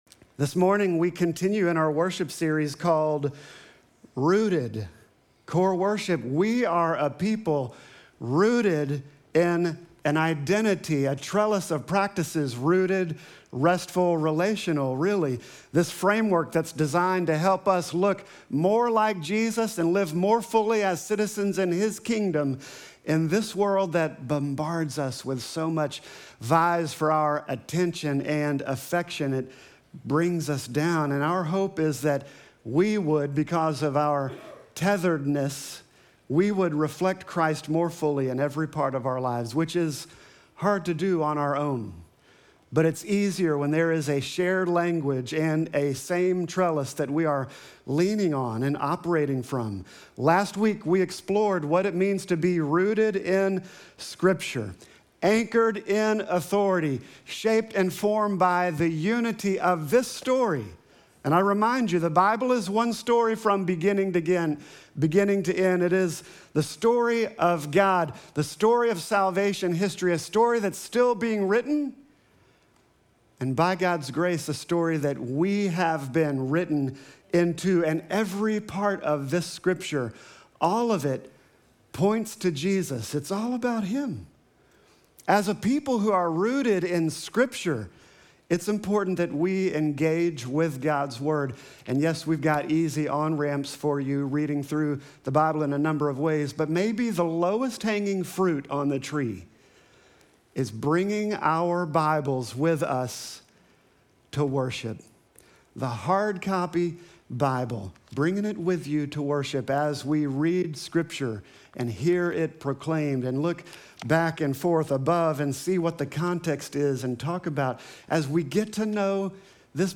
Sermon text: Deuteronomy 16:1-17